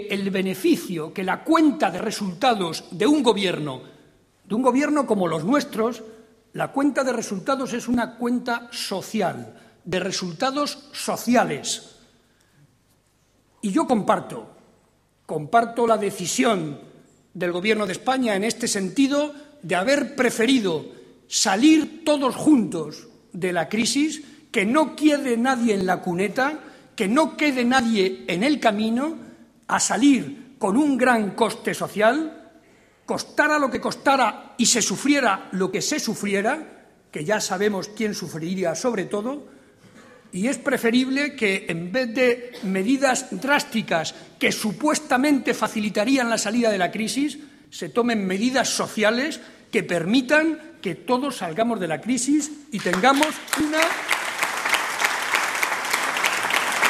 Barreda hizo estas declaraciones durante su intervención en el Comité regional del PSCM-PSOE, en Toledo.
Corte sonoro Barreda Comité Regional